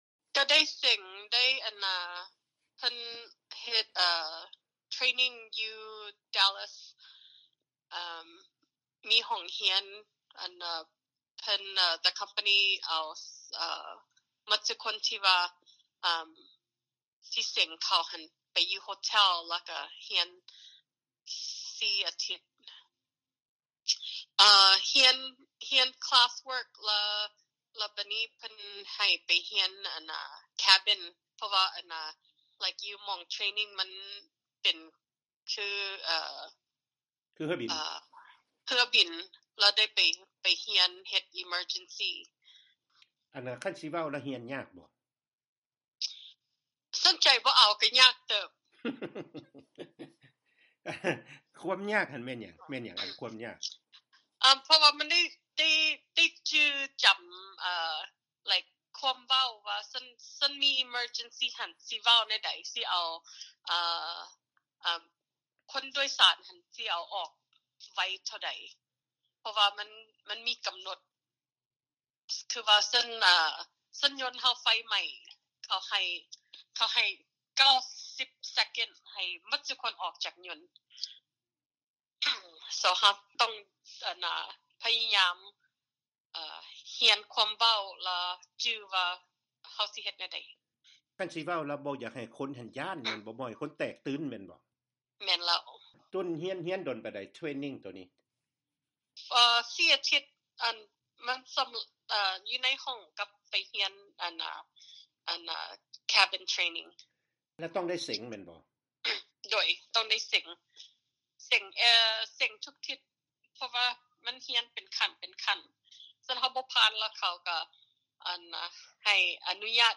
ພາສາລາວດ້ວຍສຳນຽງວຽງຈັນໄດ້ດີ.